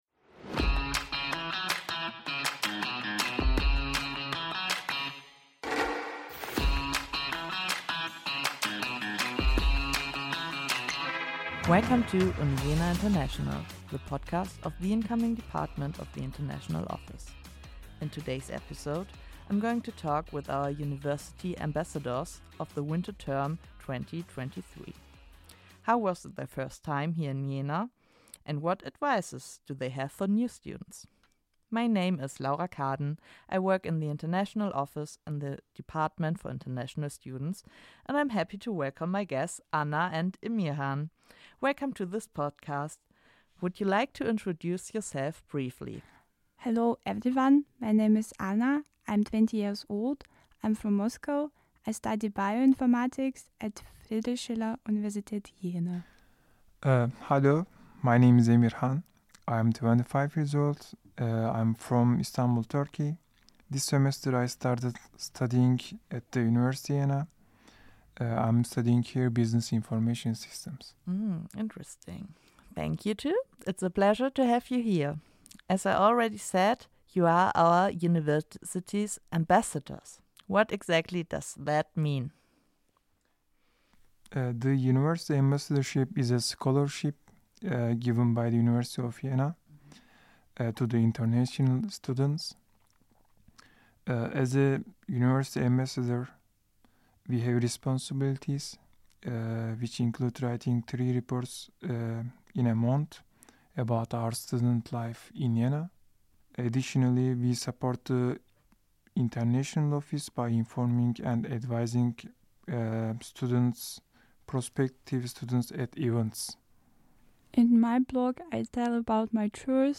In this episode an exchange student talks about her experiences at the beginning of her stay in Jena and the start of the semester.